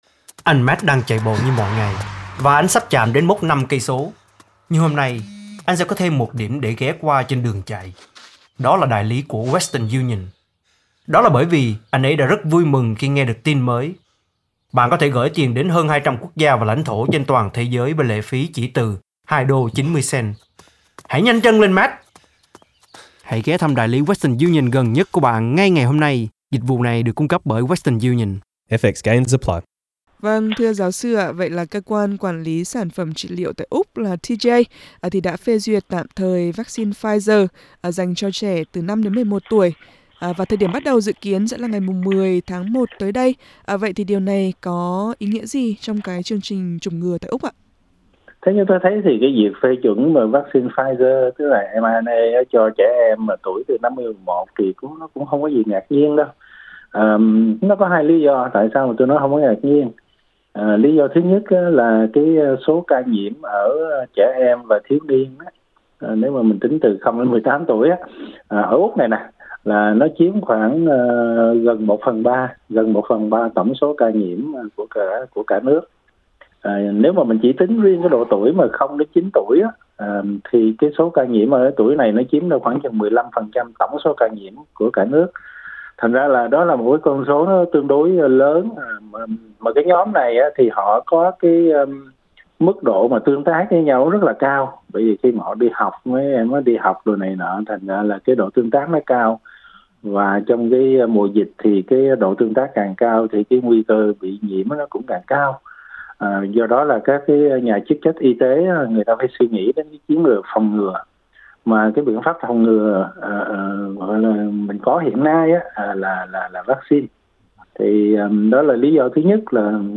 Xin mời quý vị bấm vào hình để nghe toàn bộ nội dung cuộc trò chuyện.